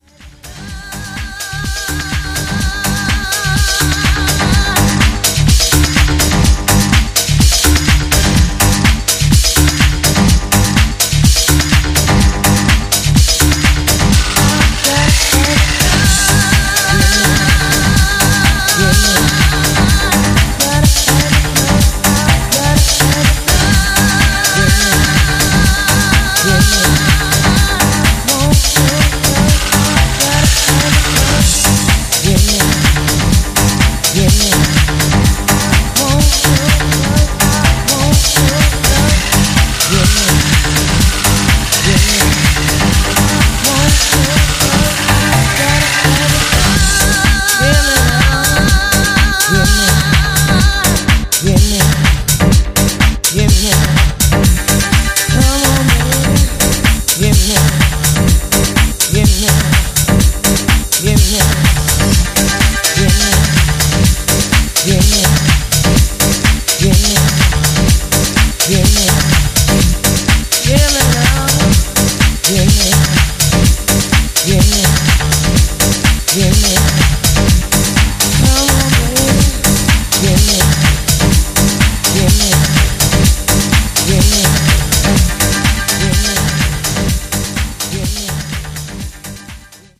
raw energy, unforgettable hooks